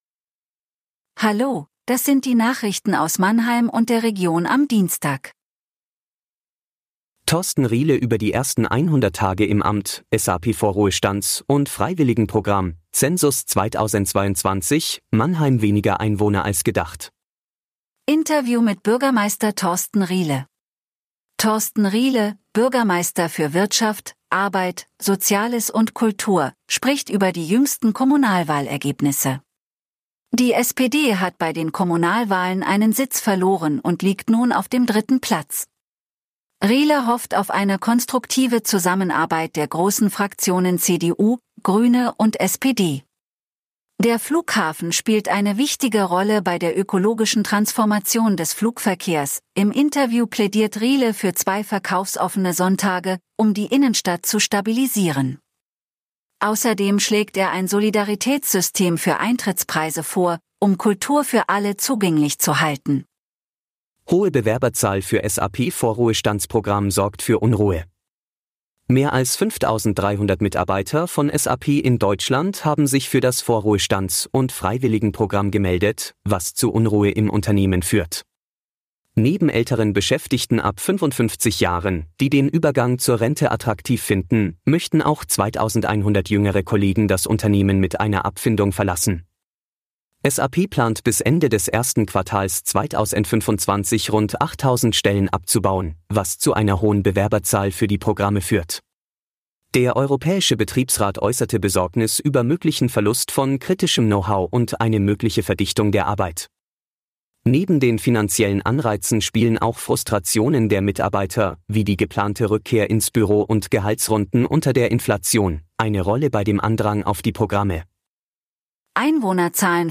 Interview
Der Nachrichten-Podcast des MANNHEIMER MORGEN